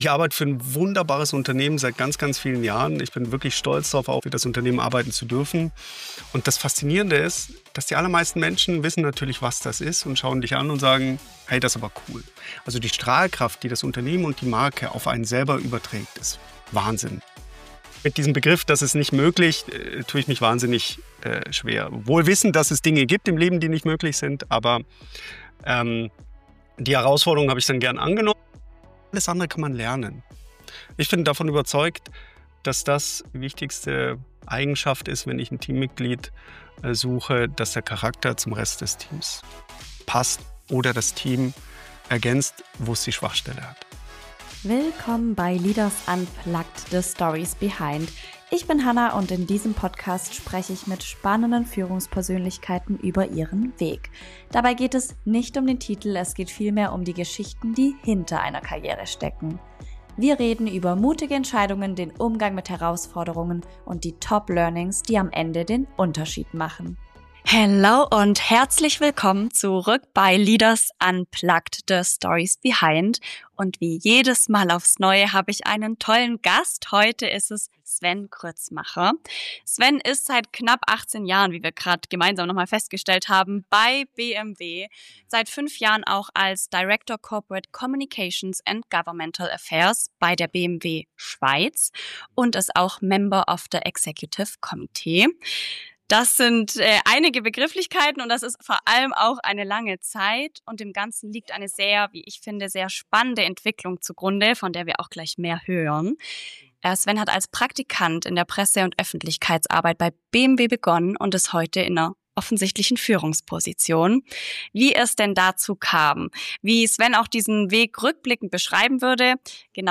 Ein Interview über Karriere ohne Masterplan, Verantwortung in unsicheren Zeiten, echte Teamkultur und die Frage, was ein erfülltes Leben am Ende ausmacht.